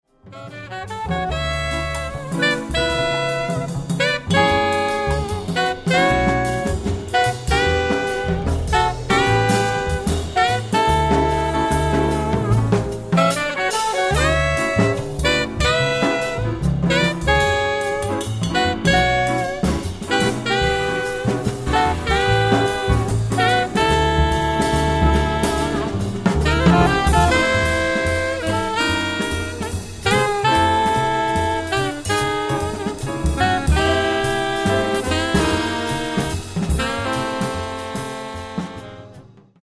Recorded live at the Y Theatre Leicester November 2007